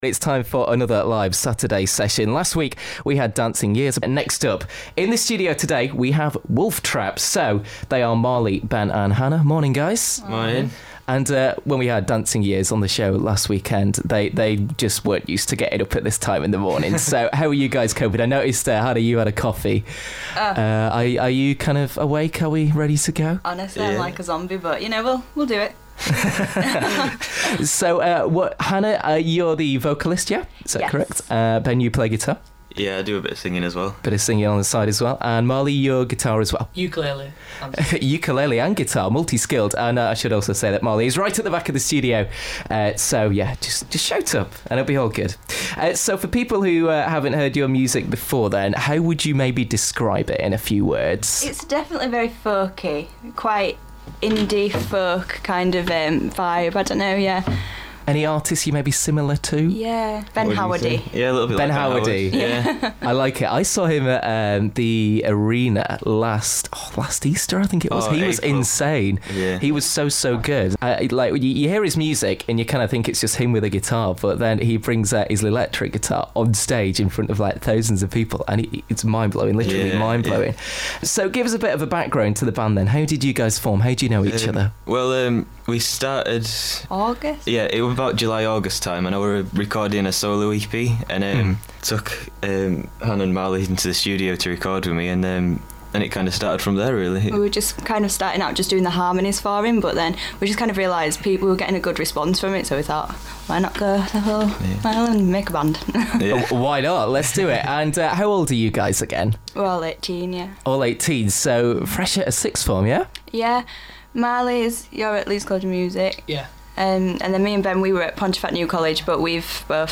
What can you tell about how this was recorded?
introduces a live session from Yorkshire unsigned band